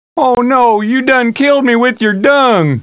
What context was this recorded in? These Deerhunter Wavs Are From A Hunting CD Game Where Deer Hunt People That I Had Years Ago